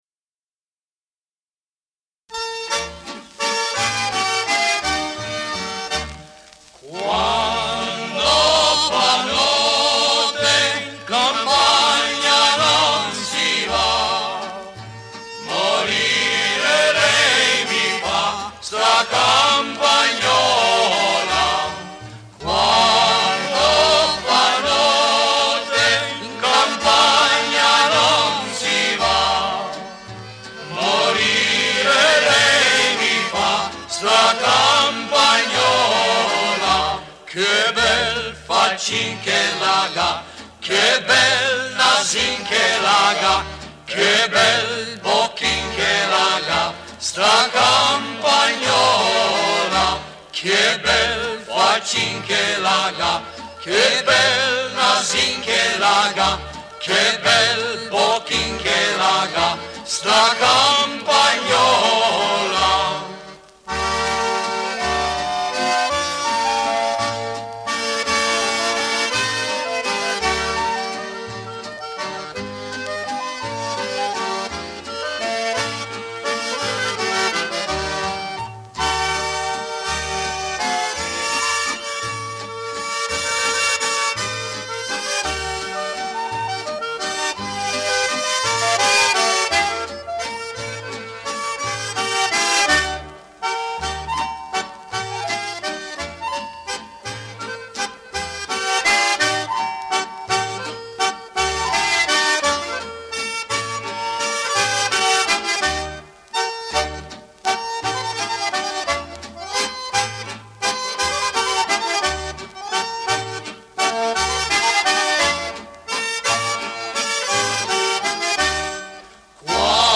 SING-A-LONG OLD ITALIAN POPULAR SONGS